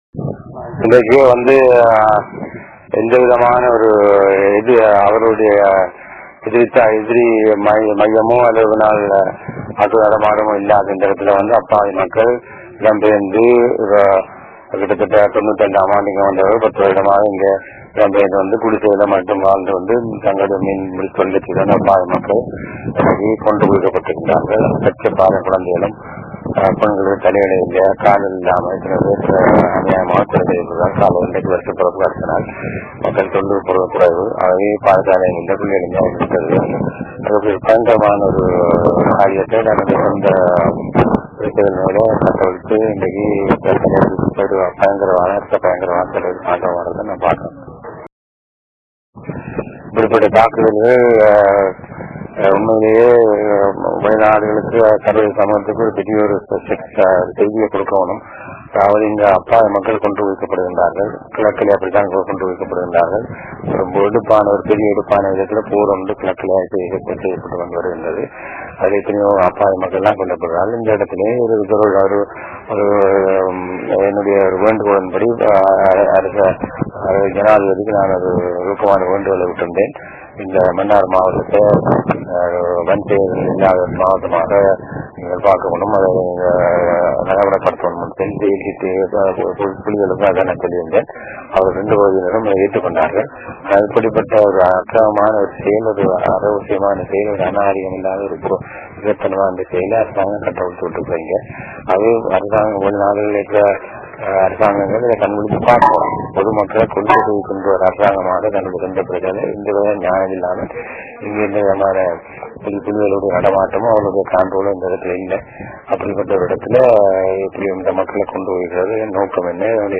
The Bishop, while addresing the media in Tamil, condemned the Sri Lankan military for telling a "barefaced lie," that the airstrike had hit a LTTE military installation.
Voice: [Tamil] Detailed address